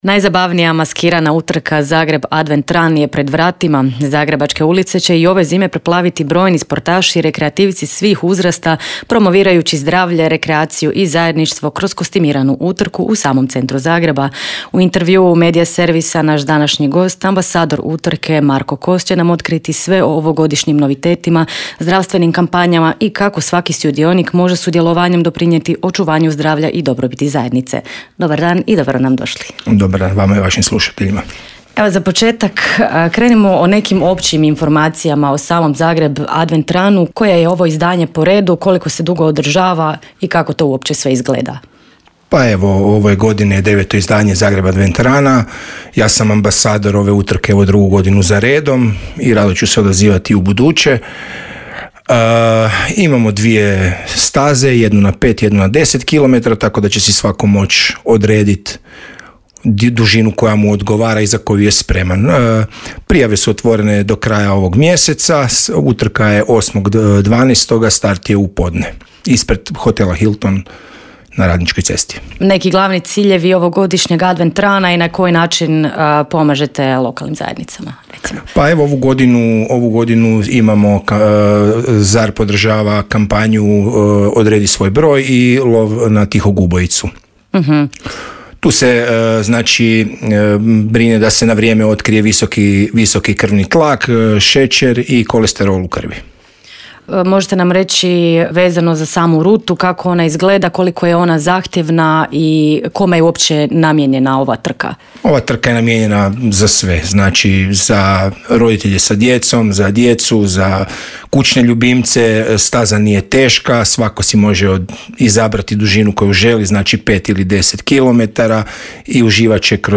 Gost u Intervjuu Media servisa